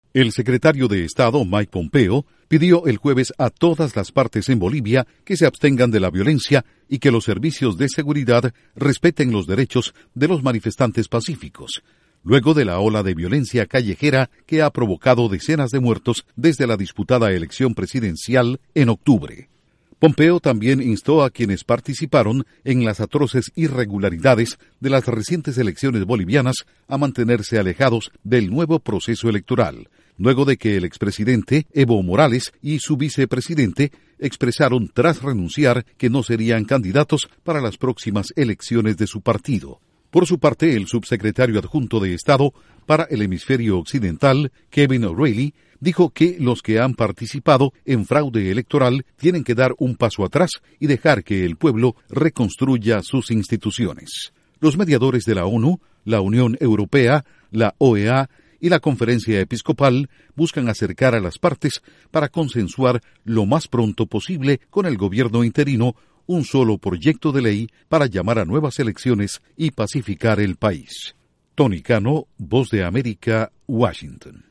informe radial